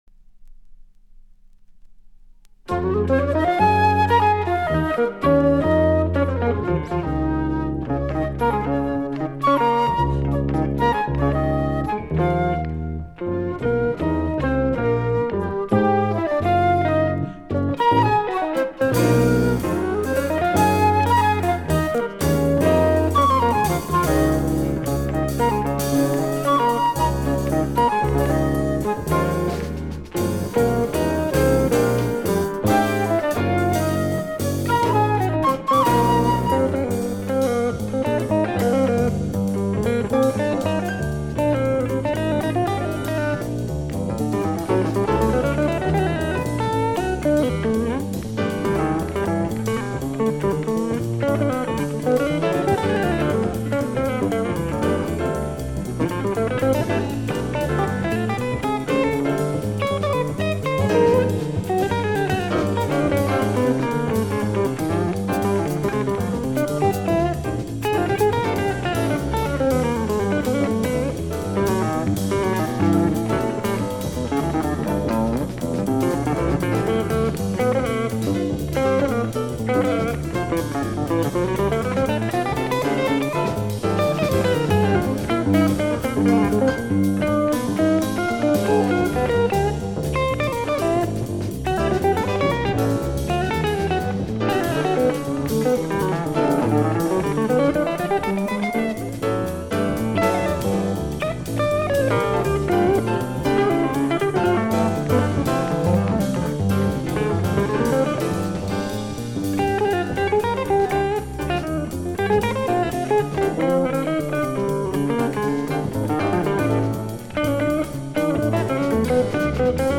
Flûte, piano, guitare, bass, drums / guitare & bass solos